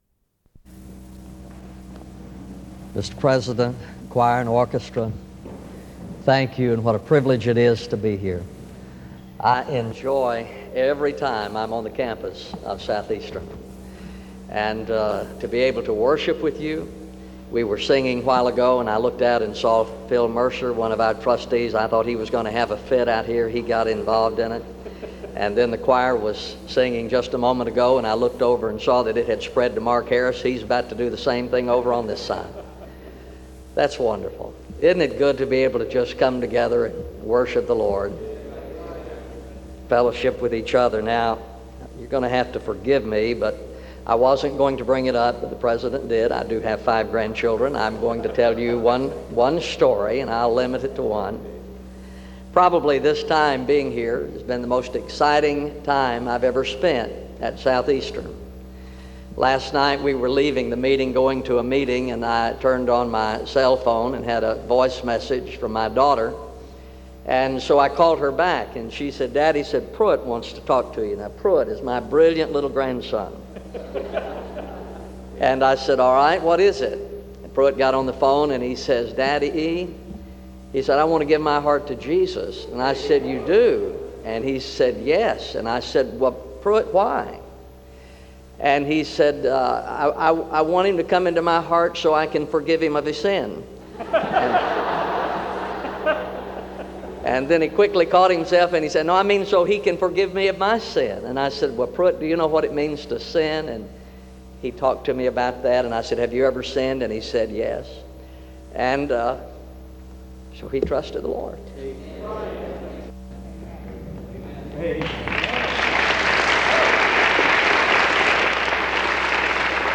SEBTS Chapel and Special Event Recordings - 2000s